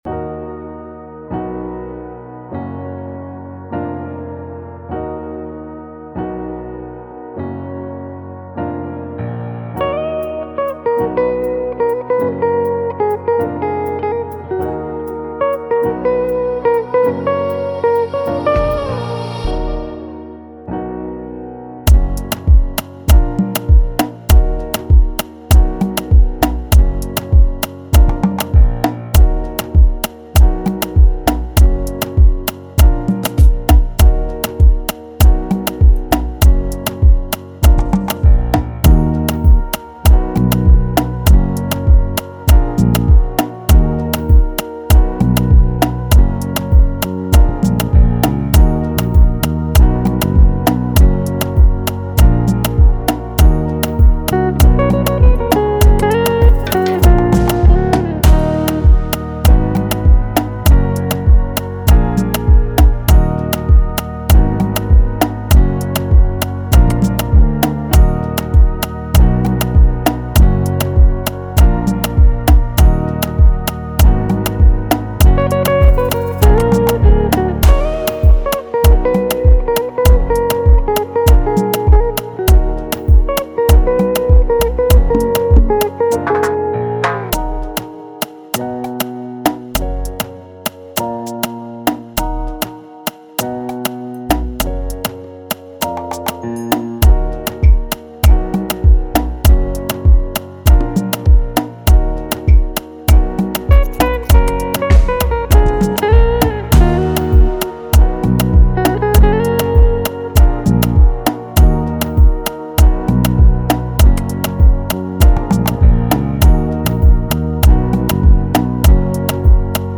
official instrumental
2021 in Dancehall/Afrobeats Instrumentals